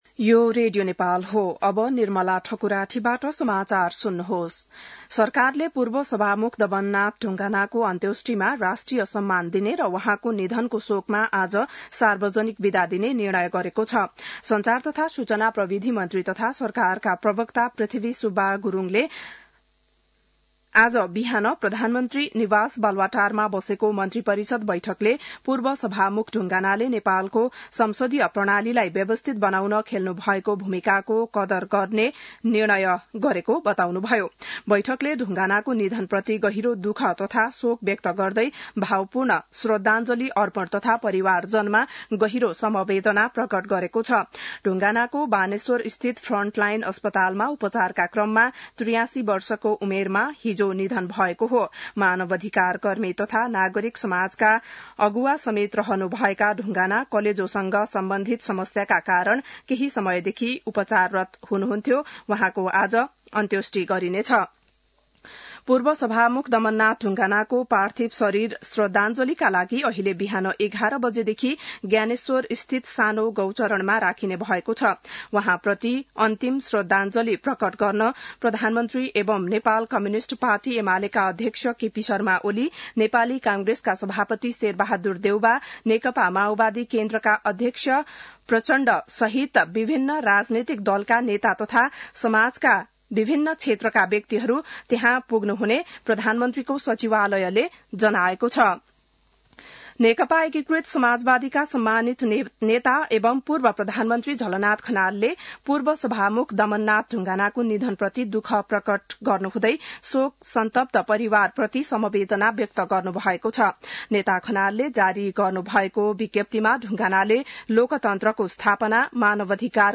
बिहान १० बजेको नेपाली समाचार : ४ मंसिर , २०८१